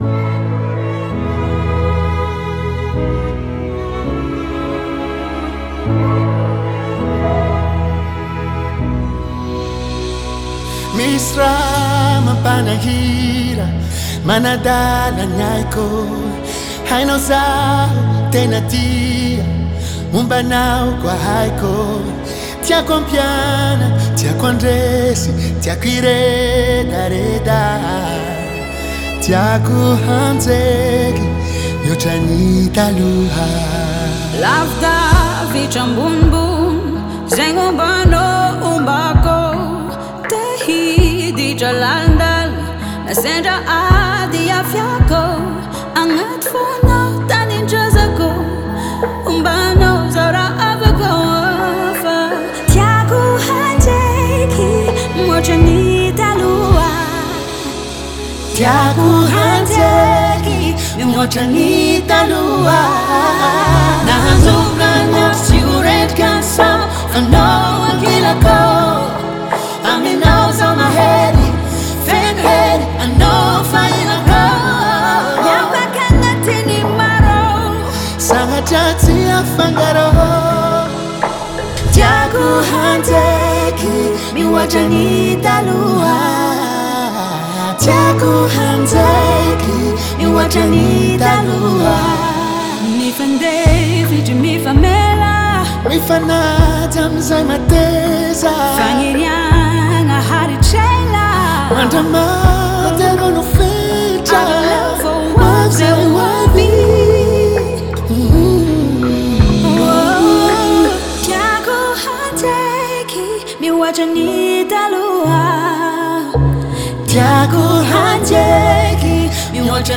два сильных голоса